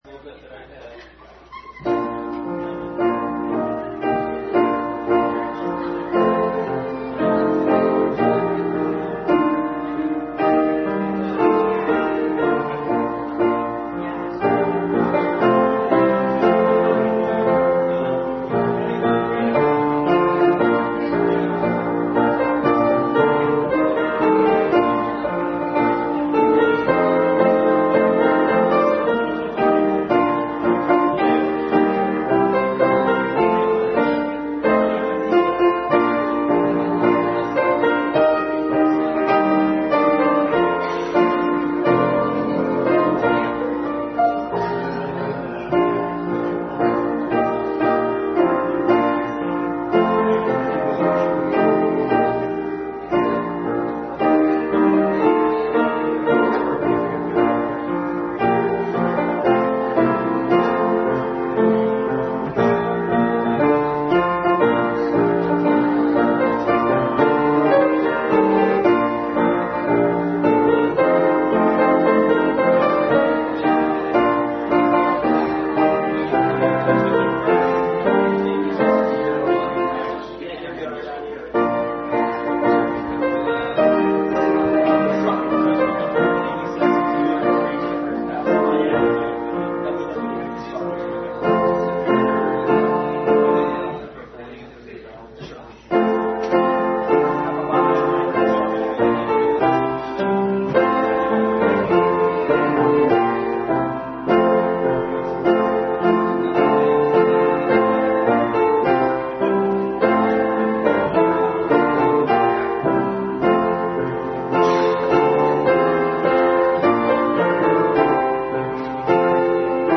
The Glory of the Resurrection Passage: Various Scriptures Service Type: Family Bible Hour